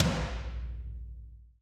TOM TOM210OL.wav